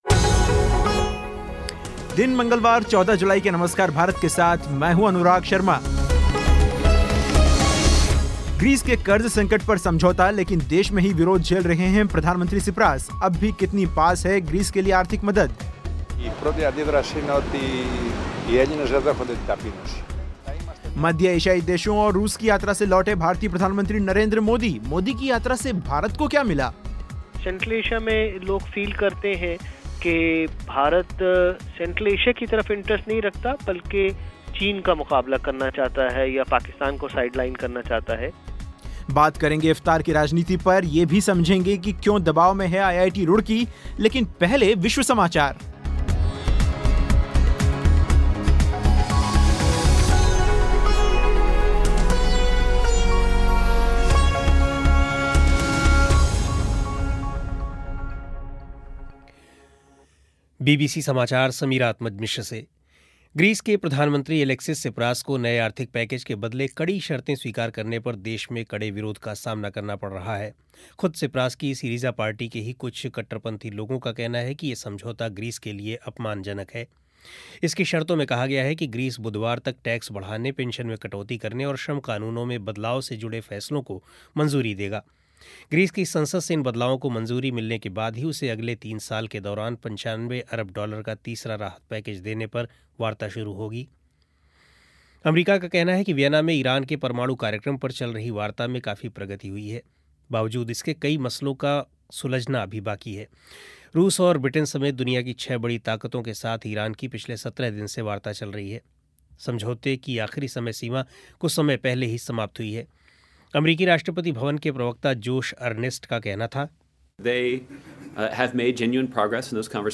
बात करेंगे इफ़्तार की राजनीति पर. ये भी समझेंगे कि क्यों दबाव में है आईआईटी रुड़की. साथ में विश्व समाचार और ये भी कि किन ख़बरों पर रहेगी नज़र.